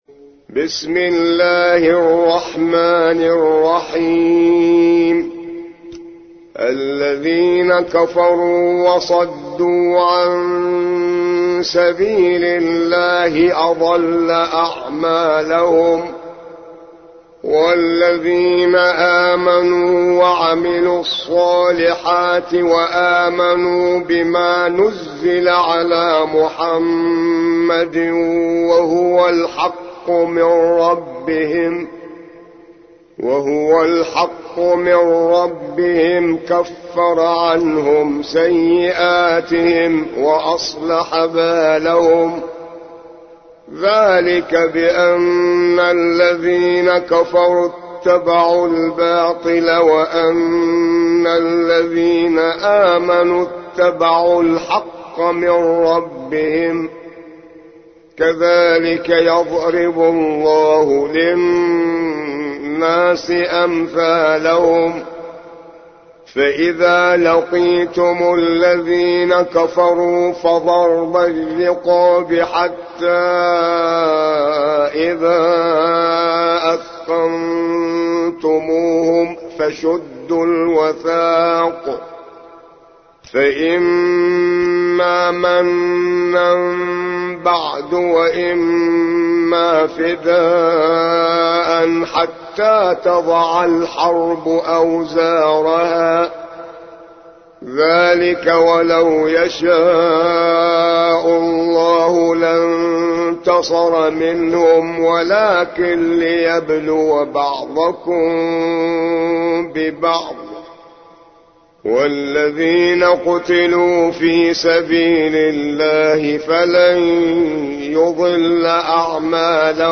47. سورة محمد / القارئ